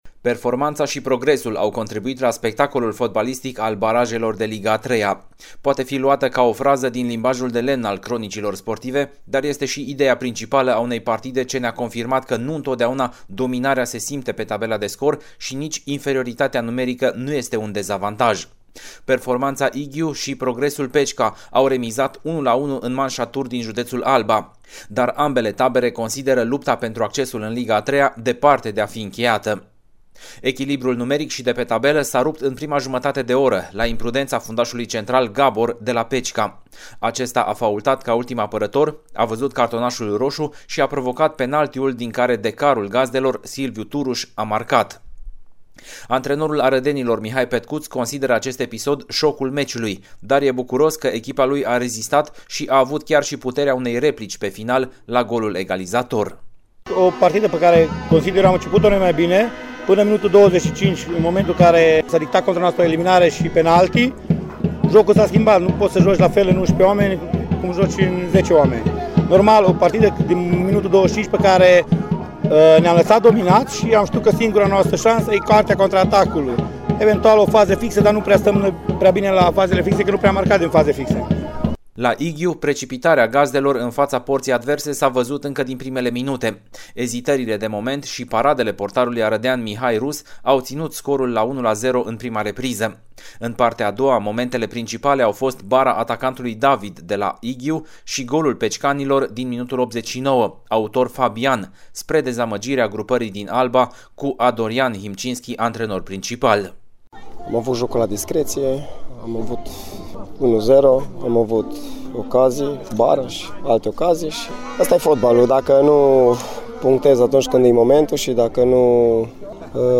Audioreportaj / Progresul Pecica poate obţine acasă „Performanţa” promovării